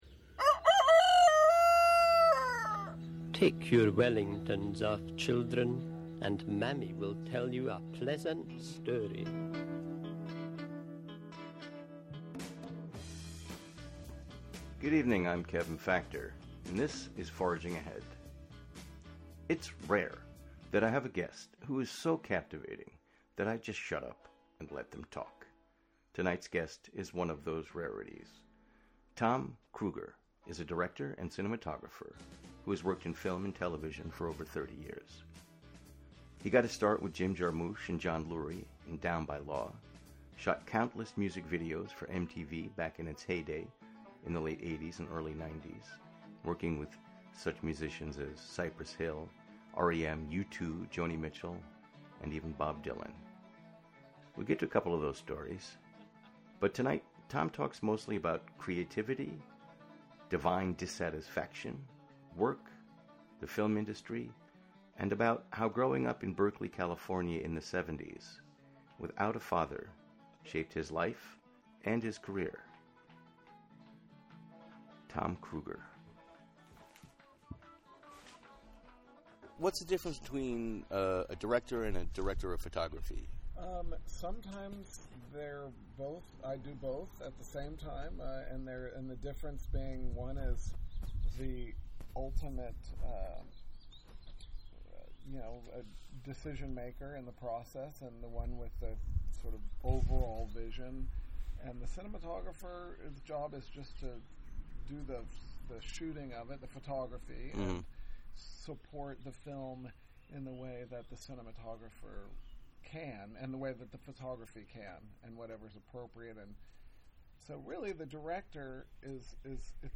Monthly program featuring music and interviews from Dutchess County resident.